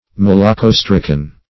Malacostracan \Mal`a*cos"tra*can\, n.
malacostracan.mp3